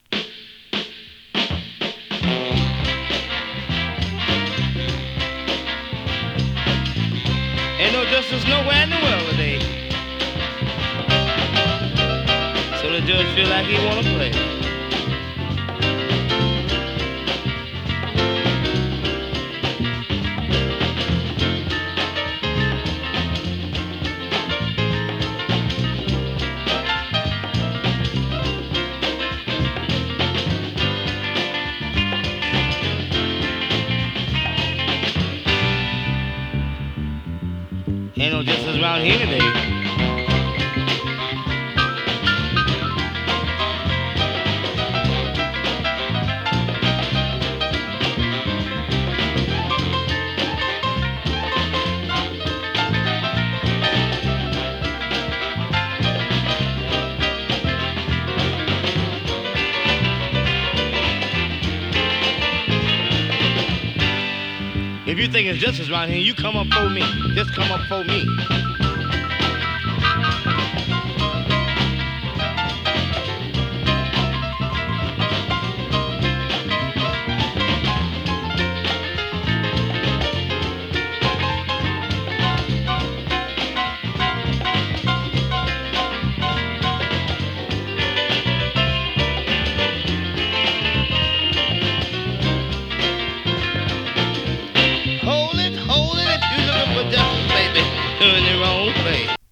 60s R＆B ファンキーソウル